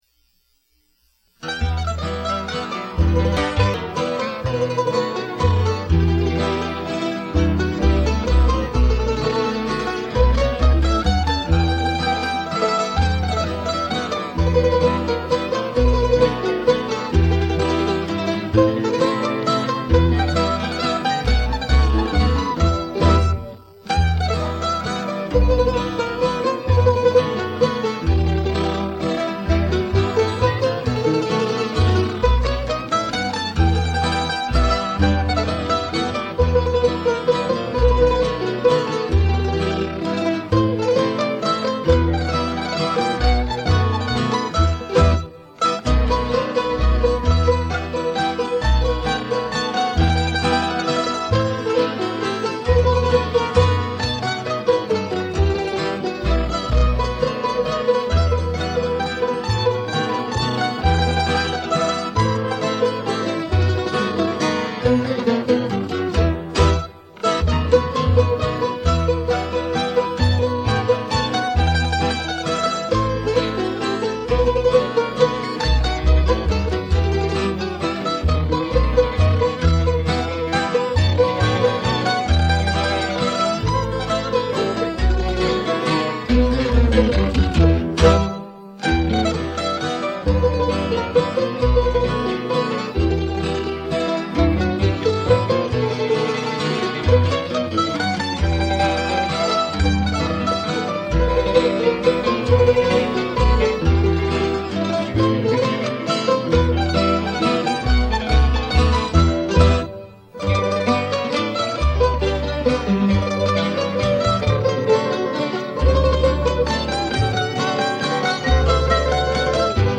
mandocello